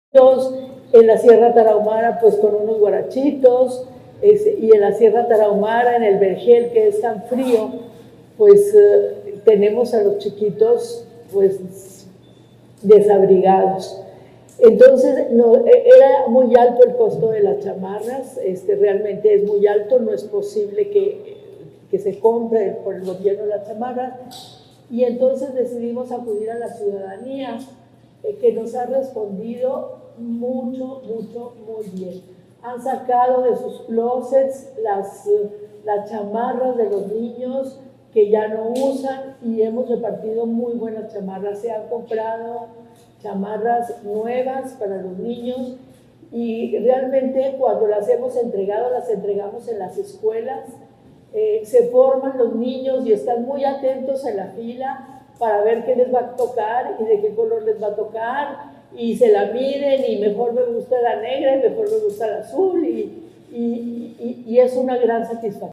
AUDIO: MARÍA EUGENIA CAMPOS ANTILLÓN, PRESIDENTA DEL DESARROLLO INTEGRAL DE LA FAMILIA ESTATAL (DIF)